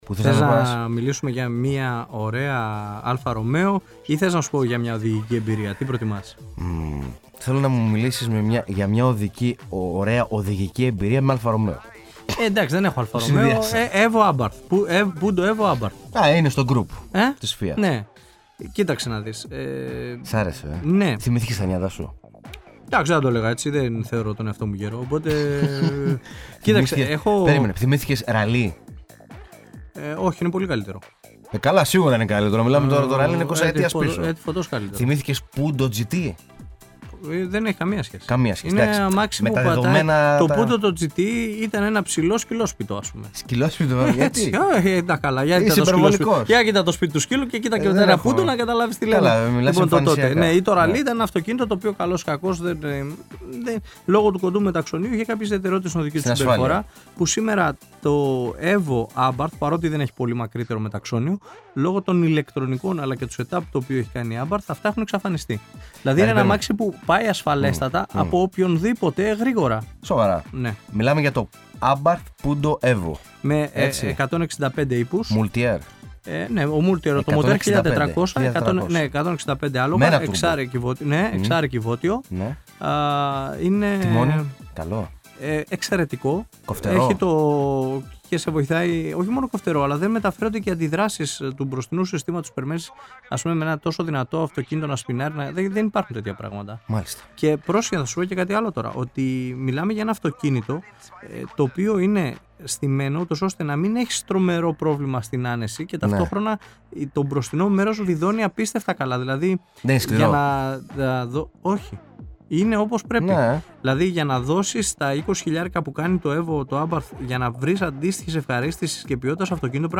στην χτεσινή ραδιοφωνική τους εκπομπή στο Ράδιο9 στους 98,9.